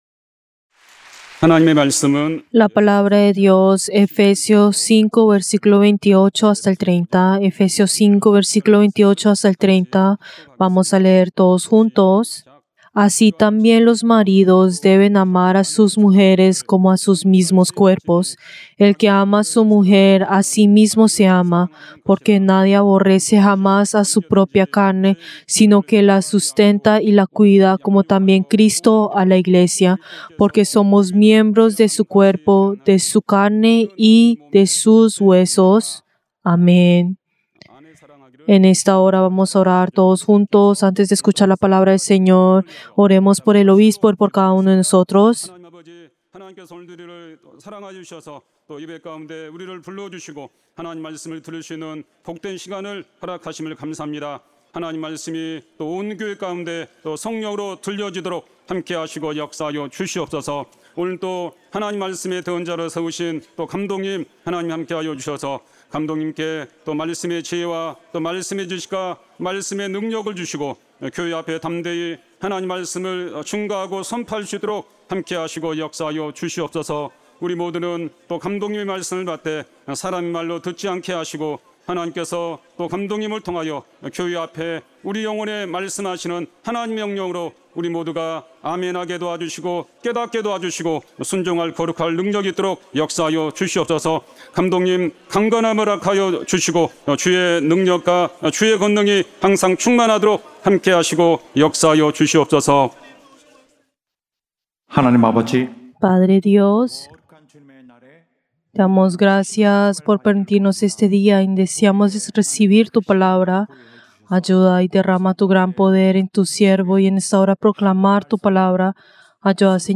Servicio del Día del Señor del 23 de marzo del 2025